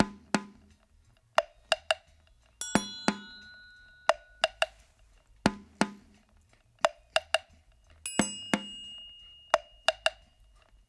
88-PERC2.wav